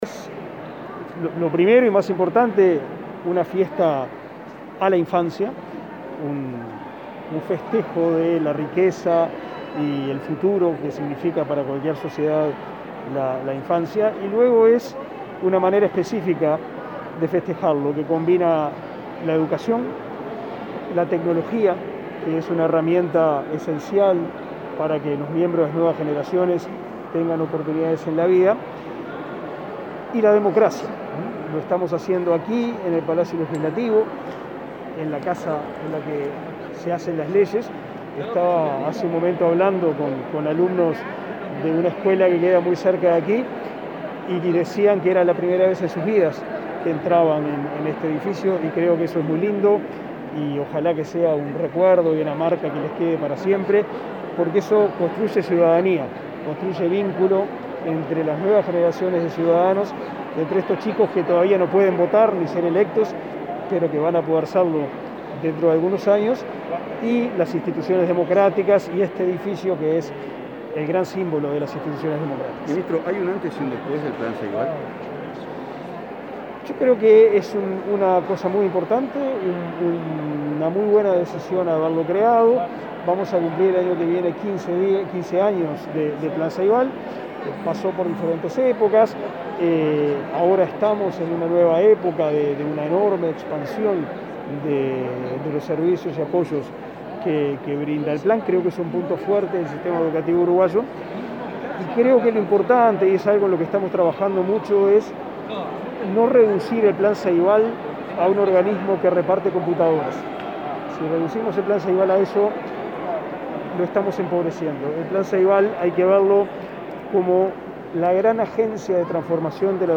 Declaraciones del ministro de Educación y Cultura, Pablo da Silveira